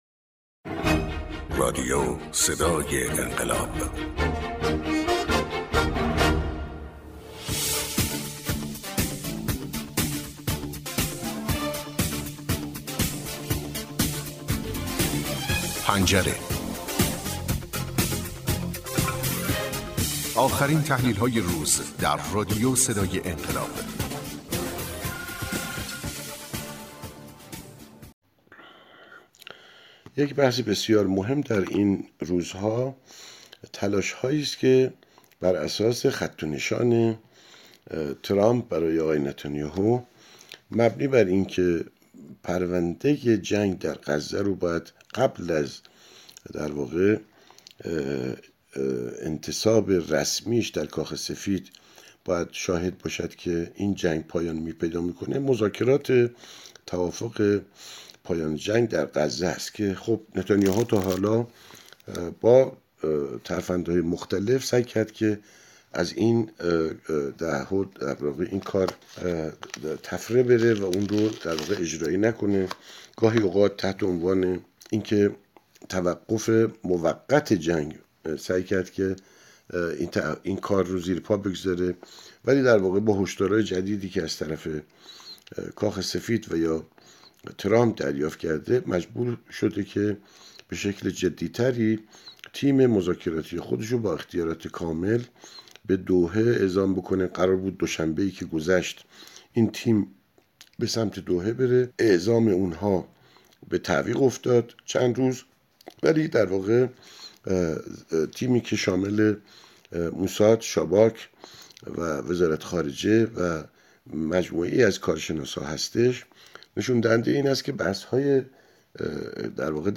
تحلیل گر منطقه و بین الملل